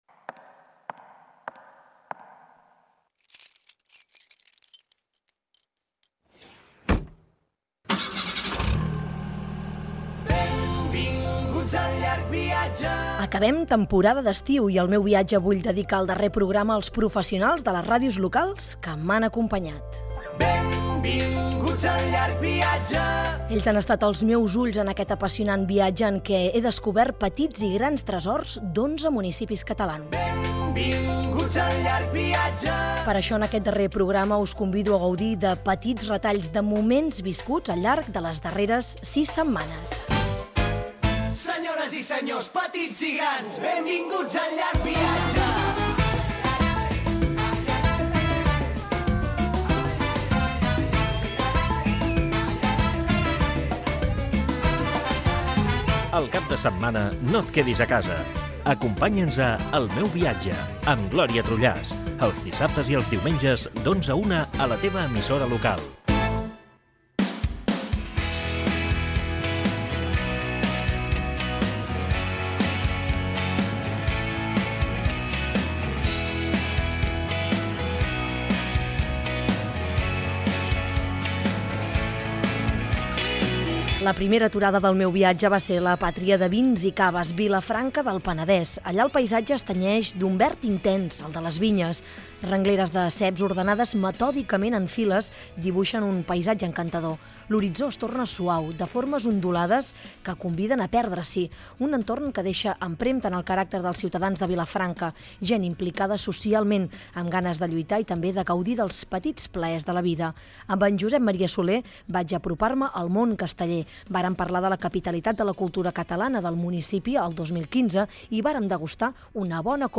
Inici del darrer programa de la sèrie. Resum del fet .Fragment de la visita a Vilafranca del Penedès.
Divulgació